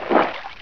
outwater.wav